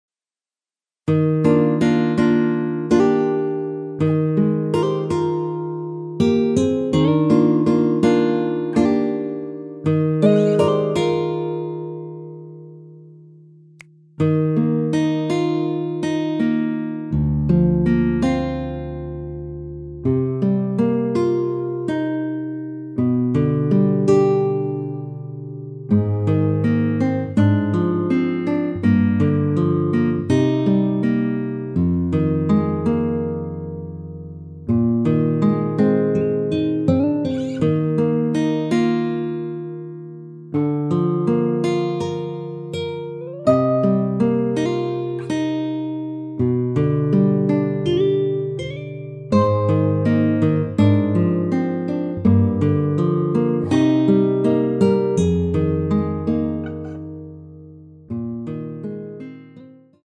Eb
◈ 곡명 옆 (-1)은 반음 내림, (+1)은 반음 올림 입니다.
앞부분30초, 뒷부분30초씩 편집해서 올려 드리고 있습니다.